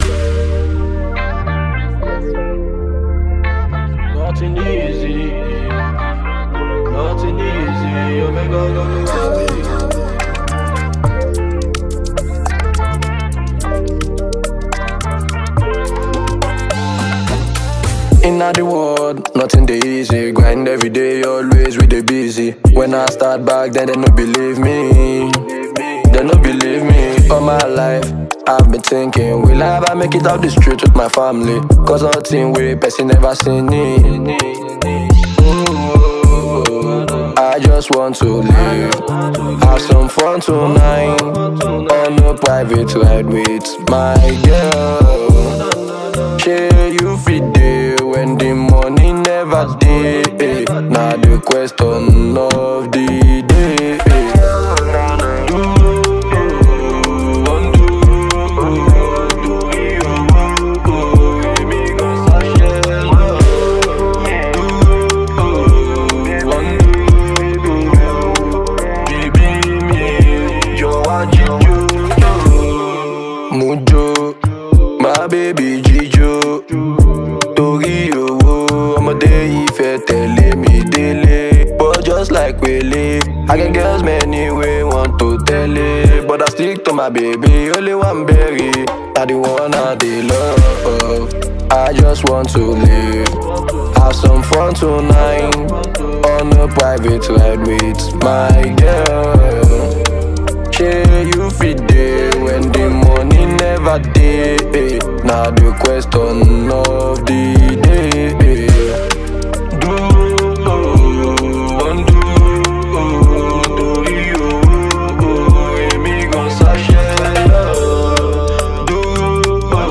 With soul-stirring lyrics and an infectious rhythm
blending pulsating beats